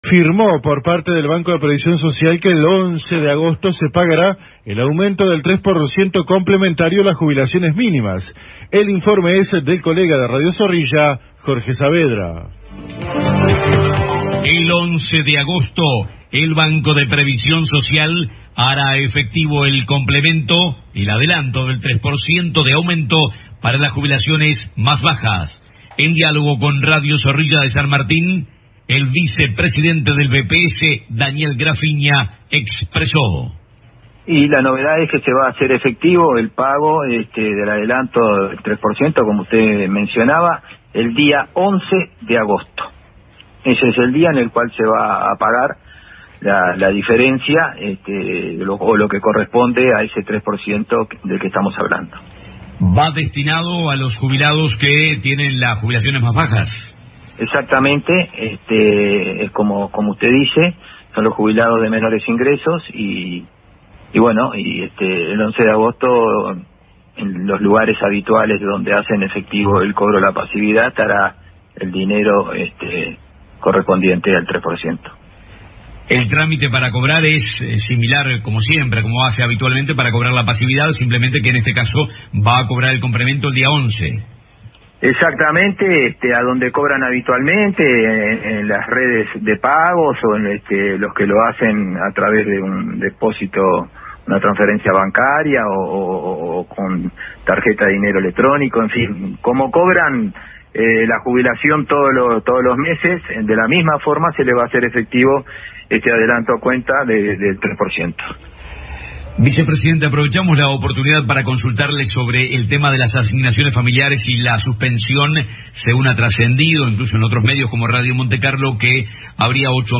Los colegas de Radio Zorrilla de San Martín conversaron con el vicepresidente del Banco de Previsión Social (BPS), Daniel Graffigna, quien informó que el próximo viernes 11 de agosto, se hará efectivo el adelanto de aumento del 3% anunciado por el Ejecutivo Nacional, para aquellos pensionistas que reciben la asignación jubilatoria más baja.